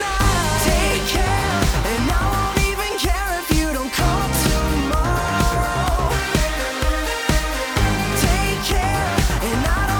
Original Track -15.1 LUFS